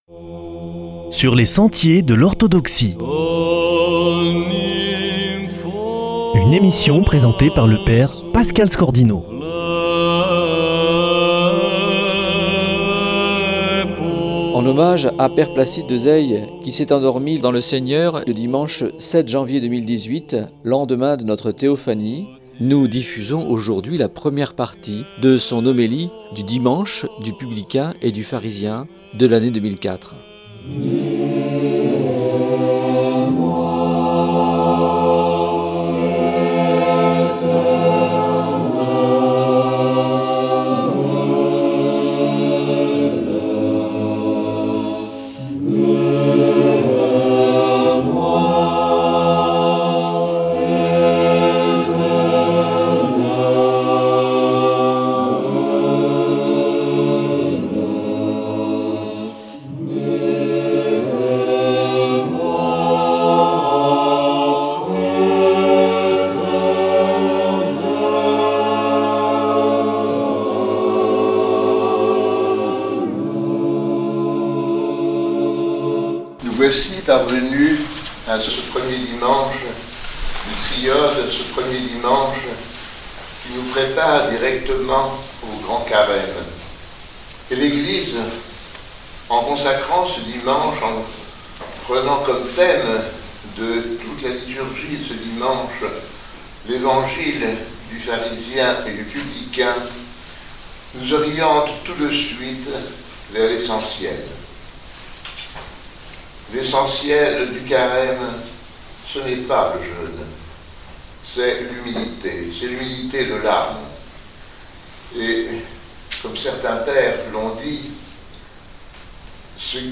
homélie dimanche du Publicain et du Pharisien 2004